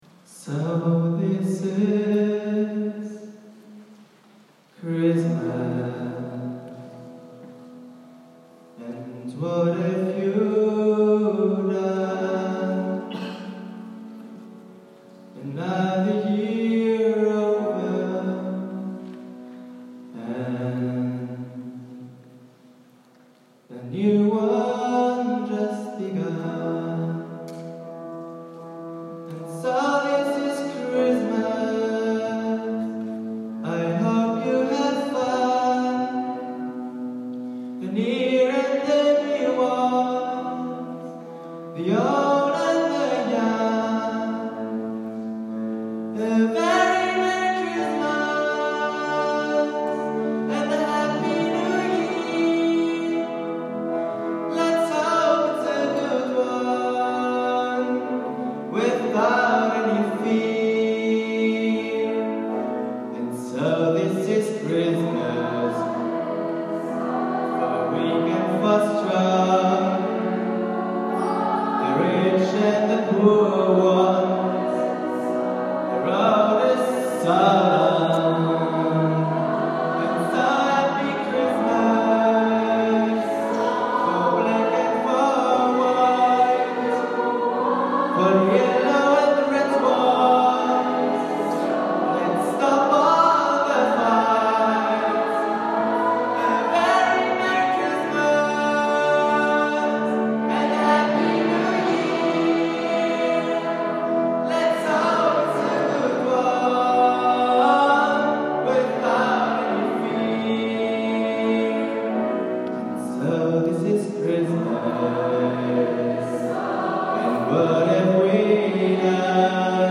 chor.mp3